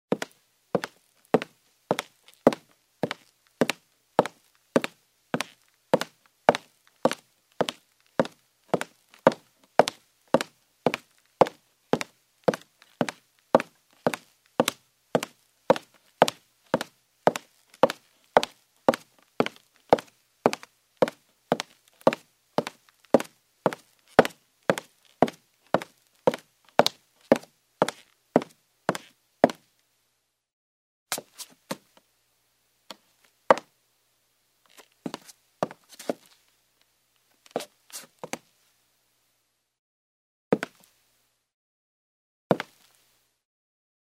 Звуки каблуков
Стук каблуков по деревянному полу